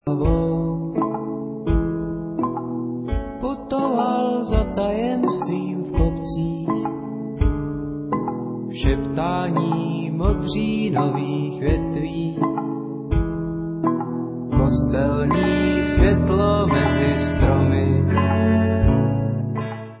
Nahrávalo se ve Zlíně